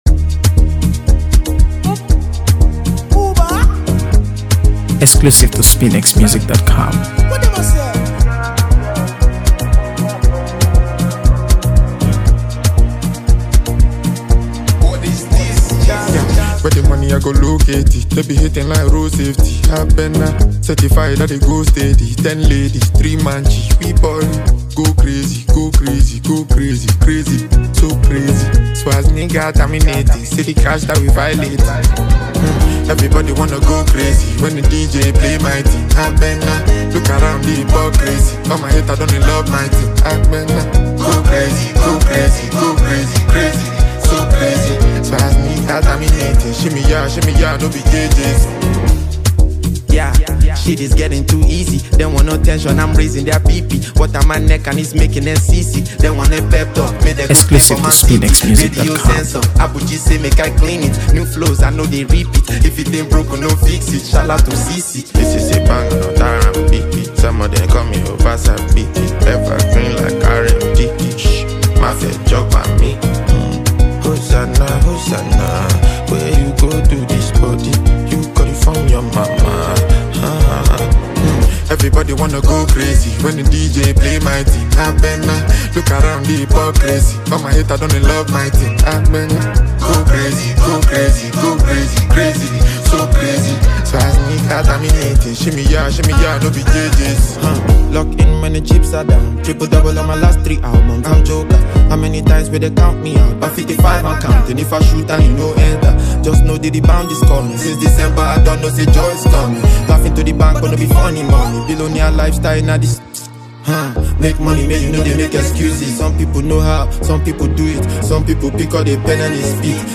AfroBeats | AfroBeats songs
Nigerian rapper
delivers sharp bars and bold charisma over a pulsating beat